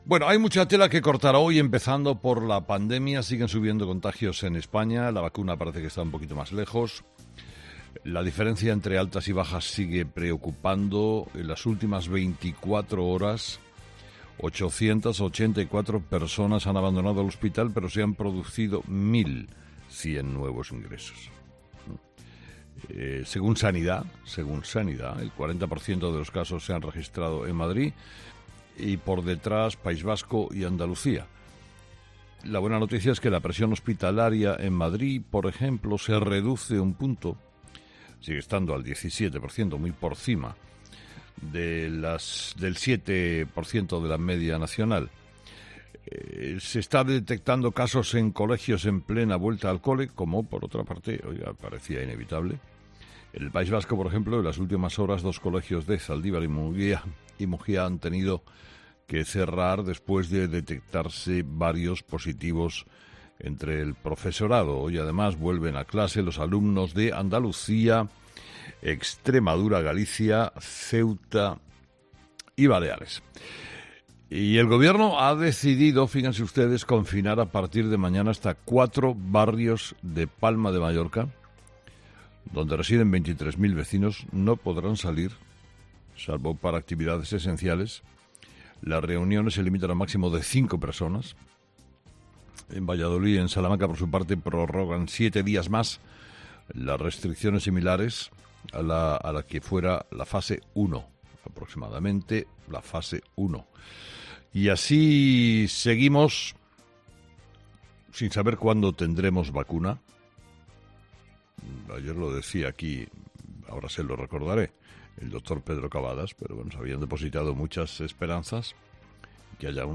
El director de Herrera en COPE, Carlos Herrera, ha analizado las informaciones sobre esta vacuna tras la entrevista realizada al doctor Cavadas